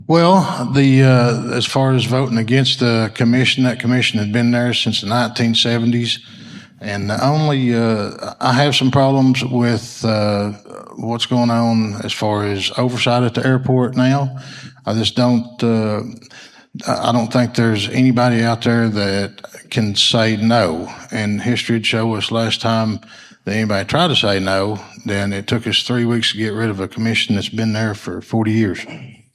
The public forum held at the Food Bank of North Central Arkansas saw County Judge Kevin Litty face challenger Eric Payne who currently serves as Justice of the Peace for District 11.